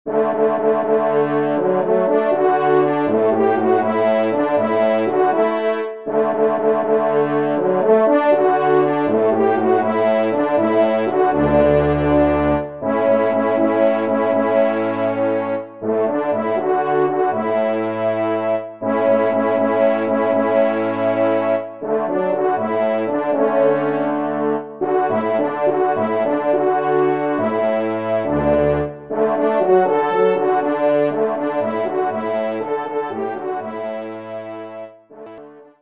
Genre :  Divertissement pour Trompes ou Cors en Ré
4eTrompe